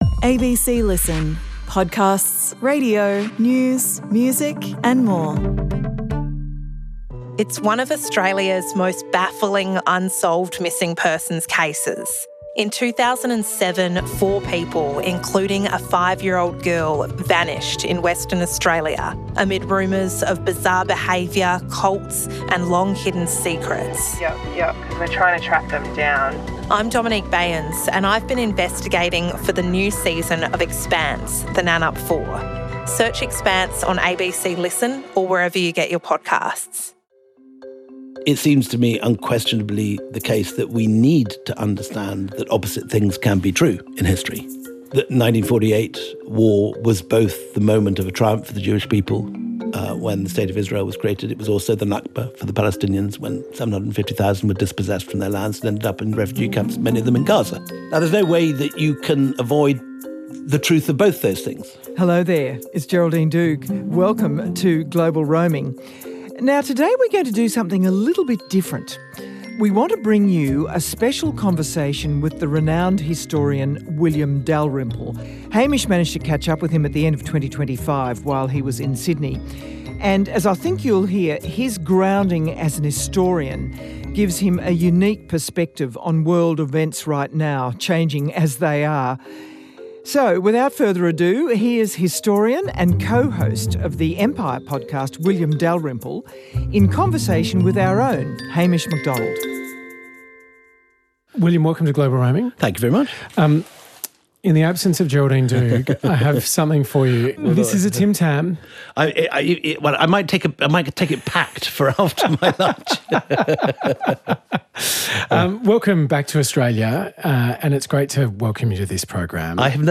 In a broad-ranging conversation, Hamish Macdonald speaks to historian and host of the hit podcast Empire William Dalrymple about the pitfalls of trying to understand the contemporary world without a firm grasp of the past, whether India can replicate its success as an ancient superpower and how countries like Australia and Britain deal with uncomfortable truths from their past.